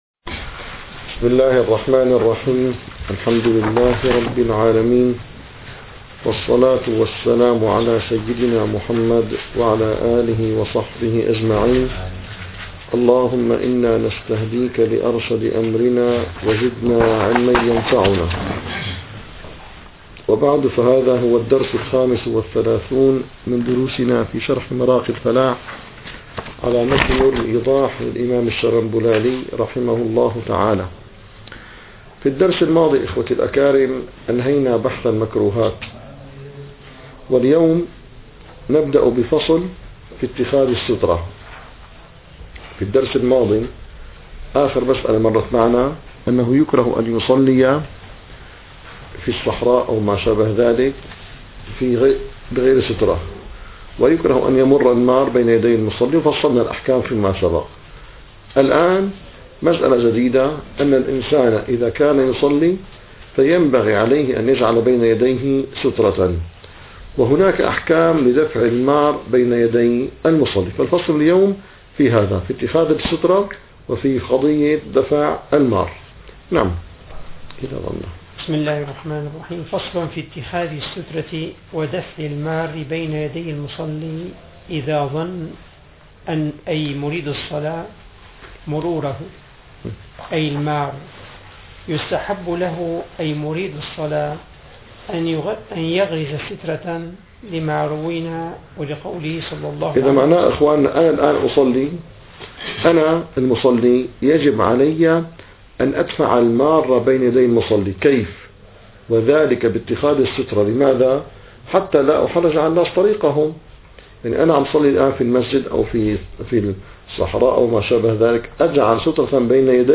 - الدروس العلمية - الفقه الحنفي - مراقي الفلاح - 35- اتخاذ سترة